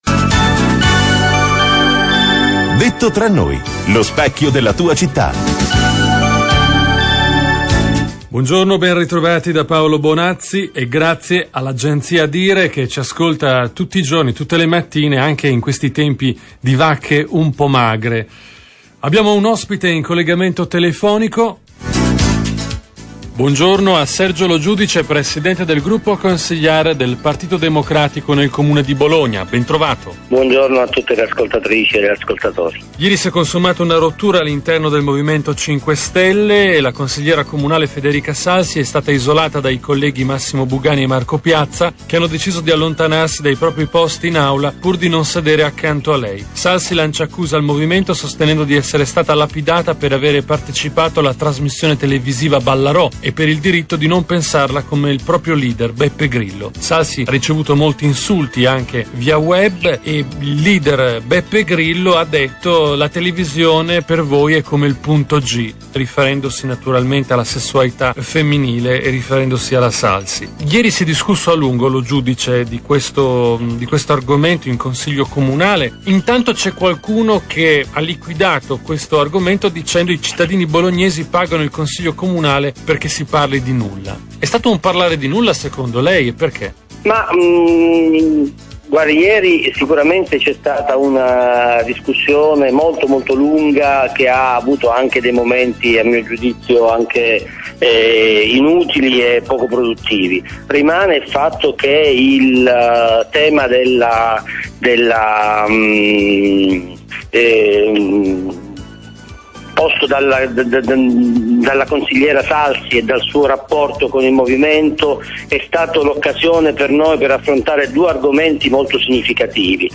Attualit� politica: il presidente Sergio Lo Giudice a Radio Tau - 6 novembre 2012
Intervista